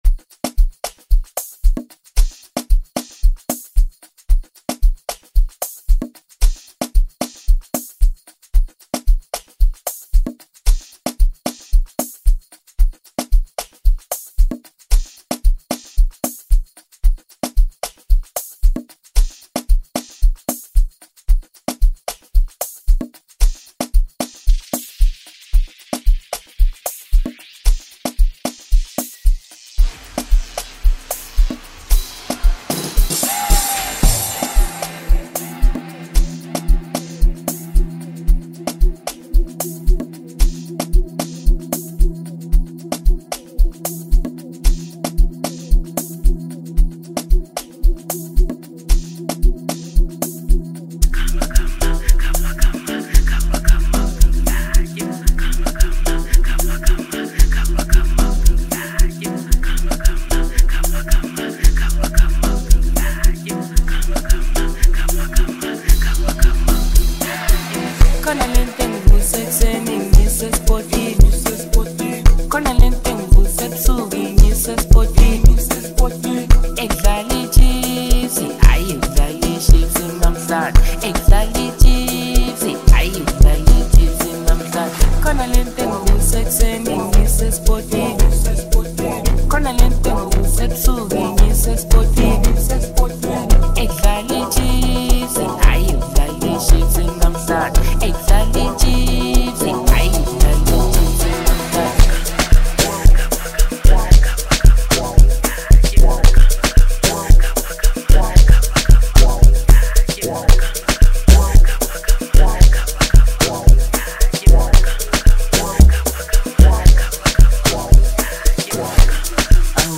talented South African singer